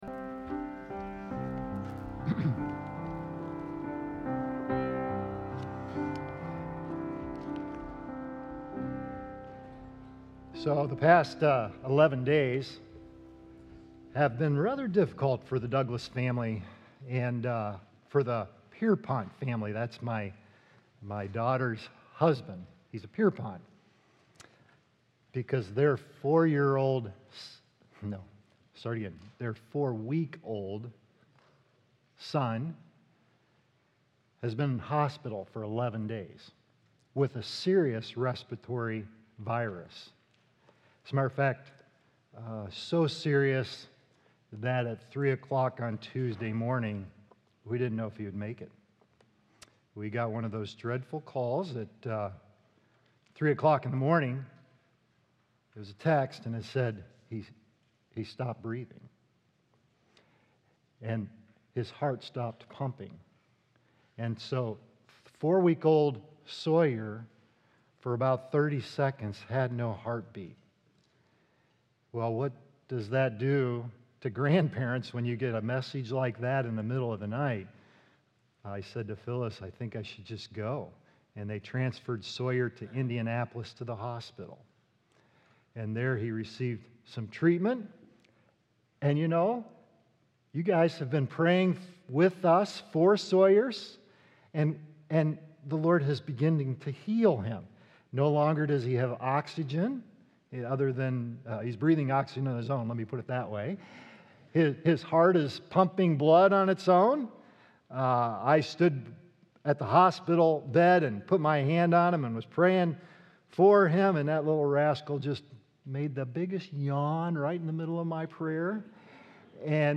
Be Free Indeed | Baptist Church in Jamestown, Ohio, dedicated to a spirit of unity, prayer, and spiritual growth